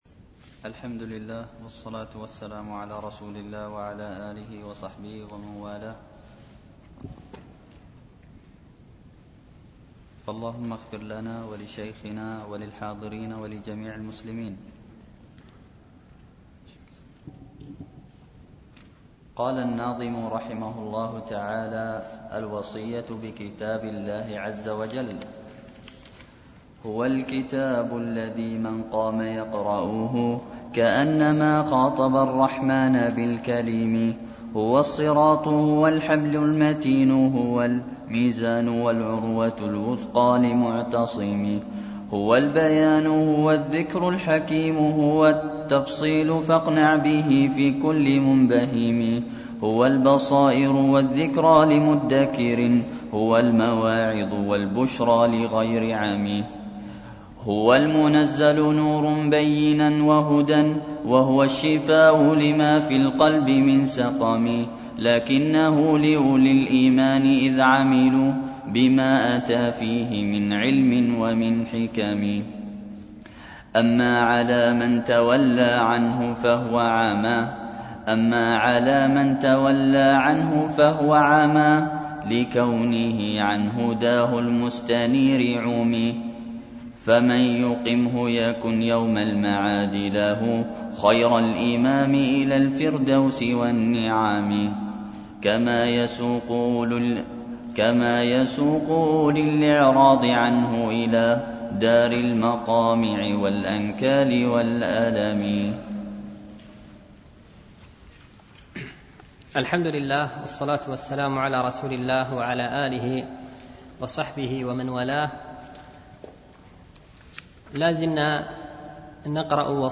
شرح المنظومة الميمية في الوصايا والآداب العلمية ـ الدرس السابع
دروس مسجد عائشة